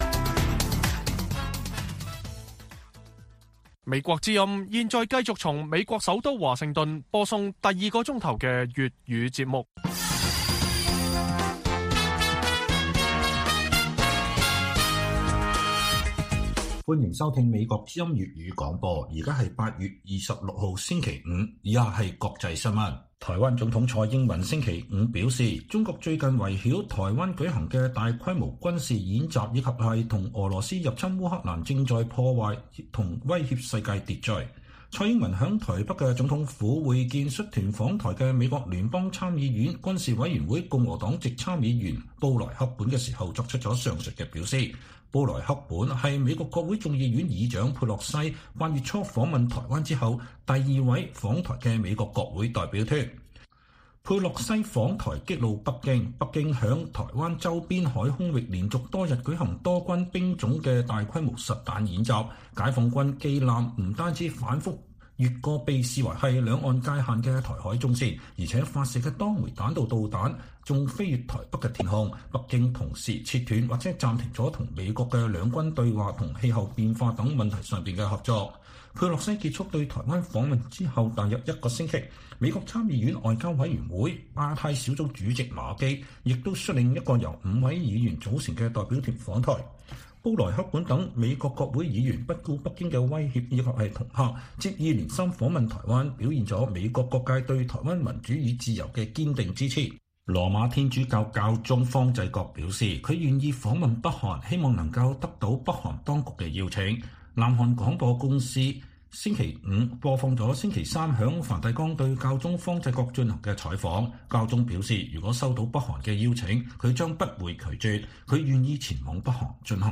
粵語新聞 晚上10-11點: 蔡英文指中國軍演和俄羅斯入侵烏克蘭正在“破壞和威脅世界秩序”